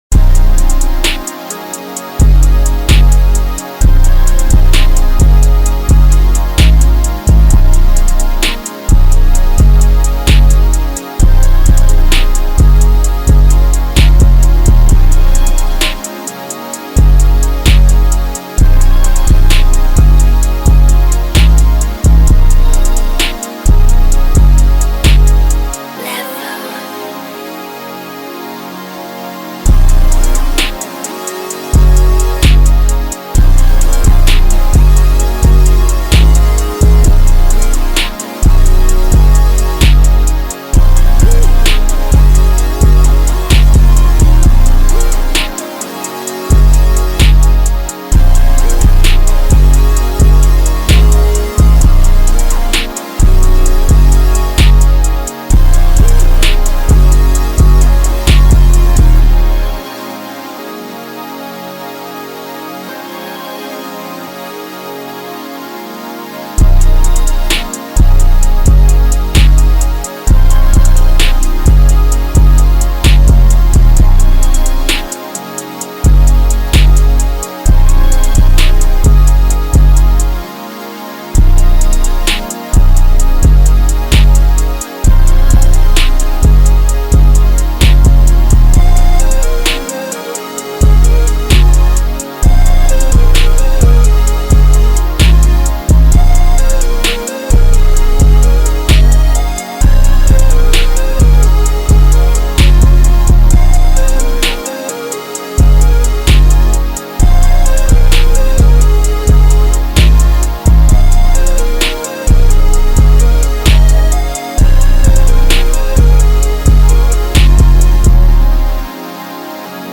official instrumental
2022 in Hip-Hop Instrumentals